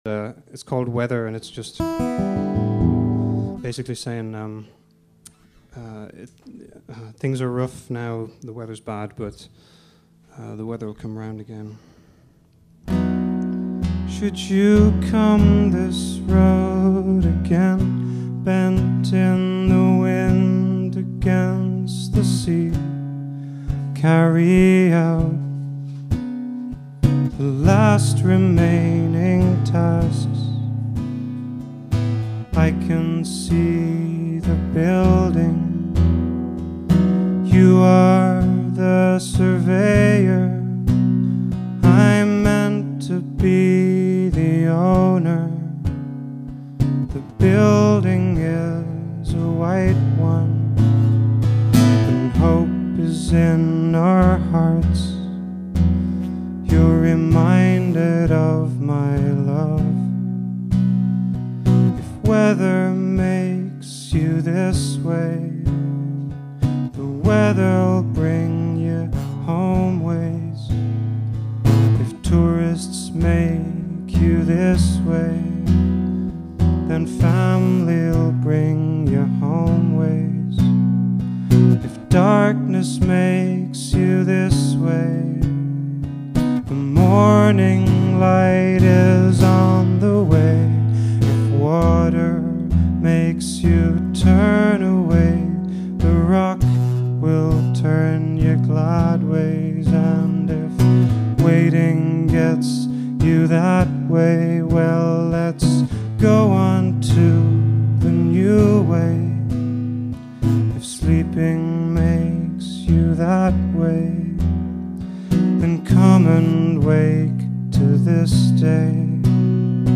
handmade (old sketches, demos, errors)